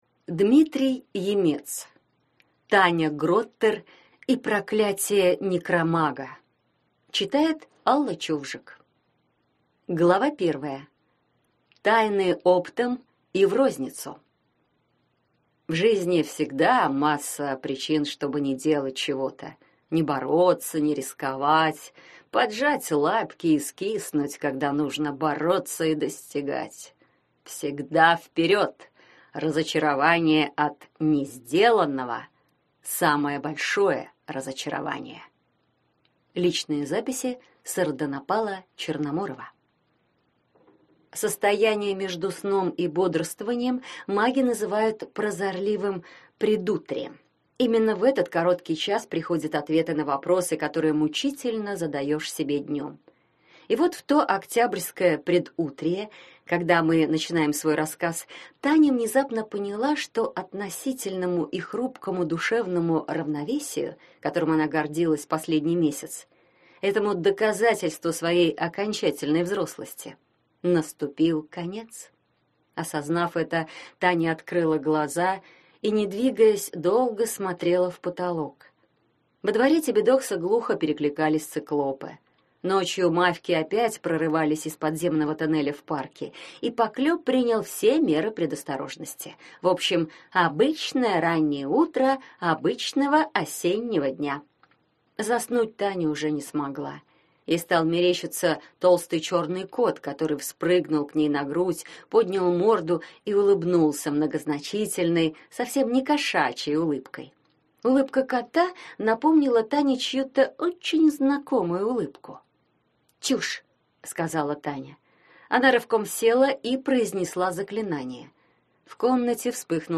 Аудиокнига Таня Гроттер и проклятие некромага | Библиотека аудиокниг